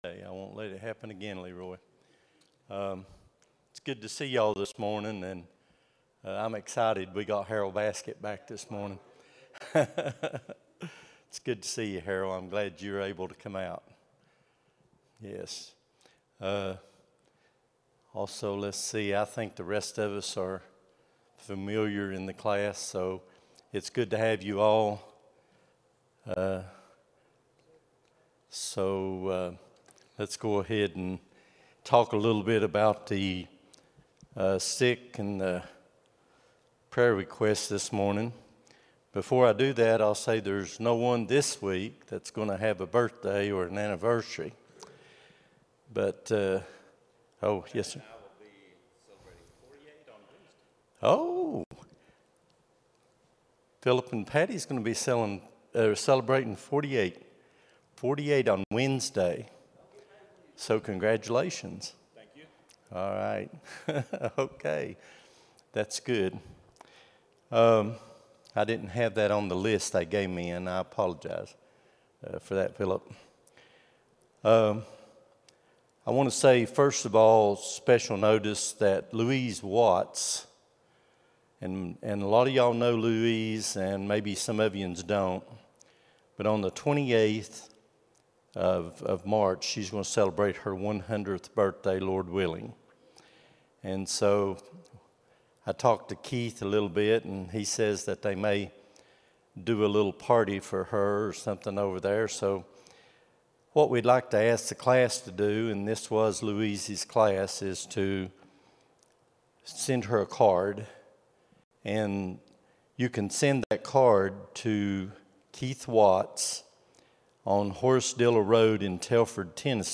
03-09-25 Sunday School | Buffalo Ridge Baptist Church